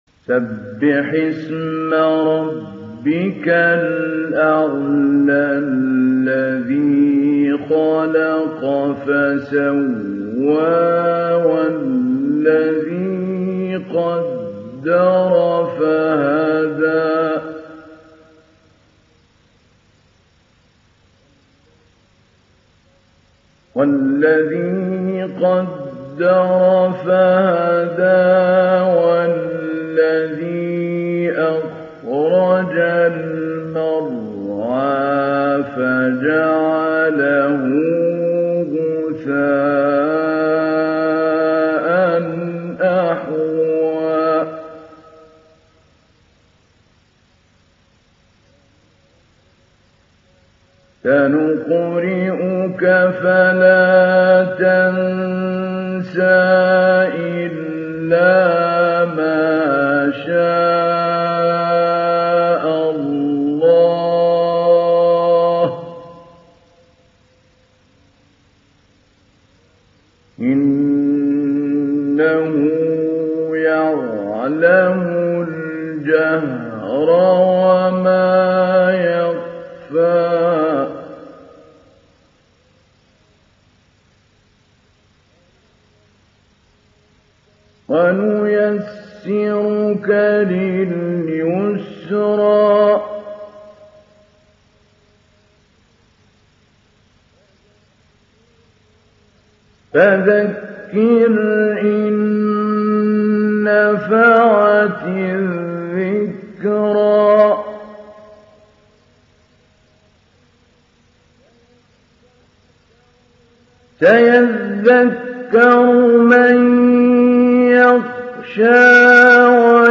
Download Surah Al Ala Mahmoud Ali Albanna Mujawwad